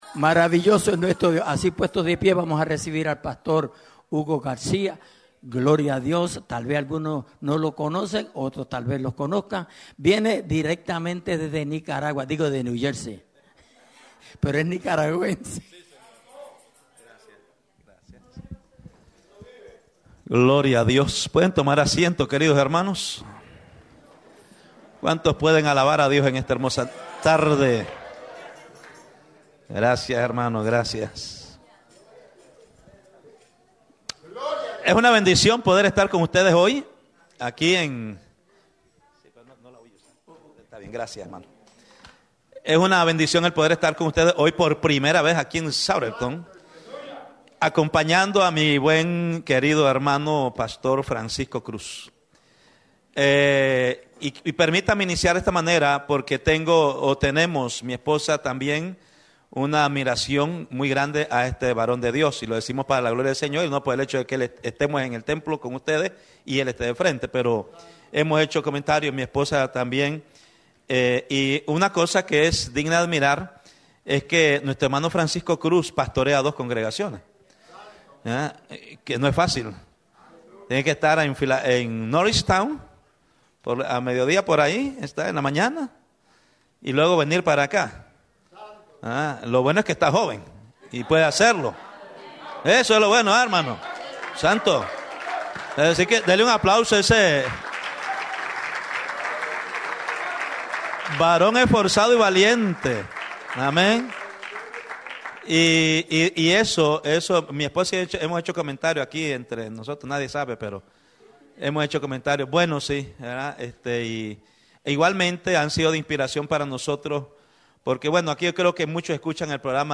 Culto Domingo